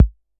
Tr8 Kick 01.wav